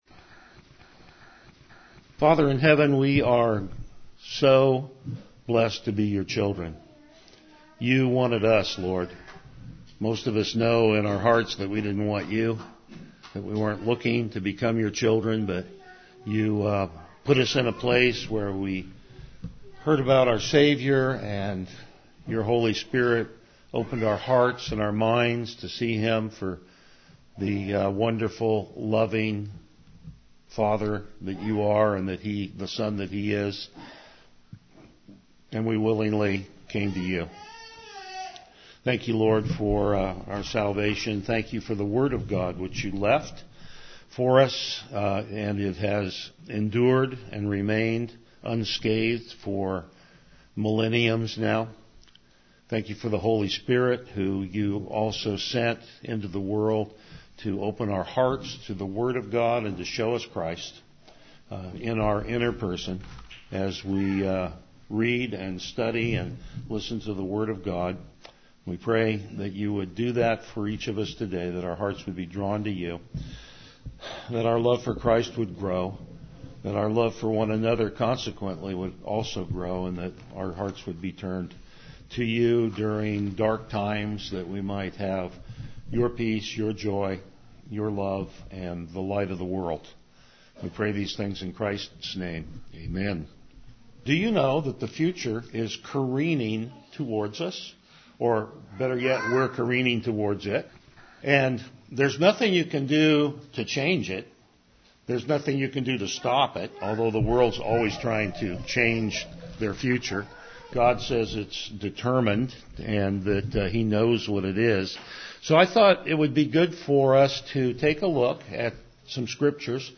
Selected Passages Service Type: Morning Worship All men need hope.